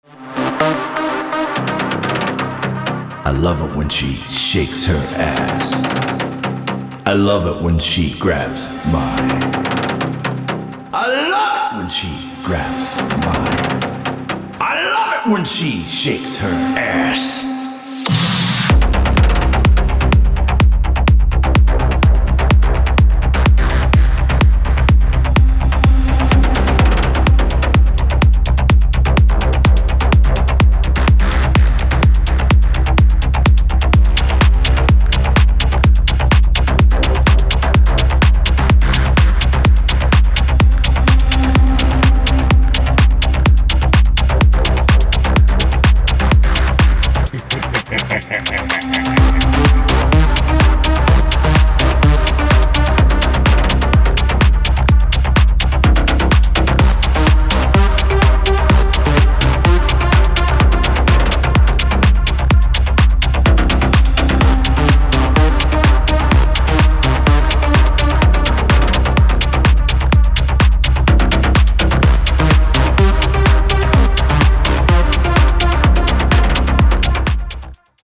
Melodic House & Techno